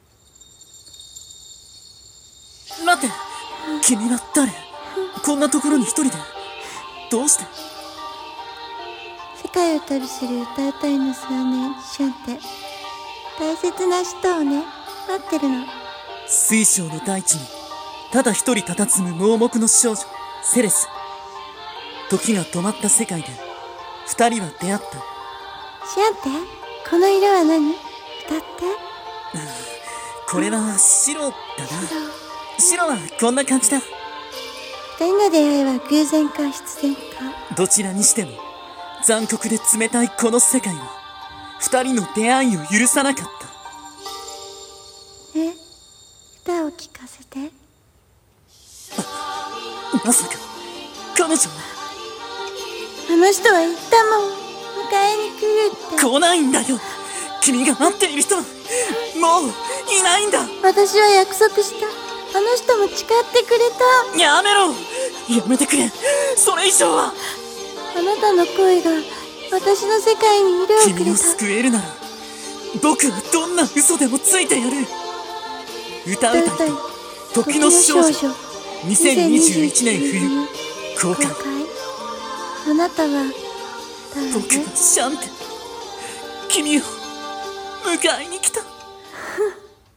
【声劇台本】歌唄いと刻の少女(二人用)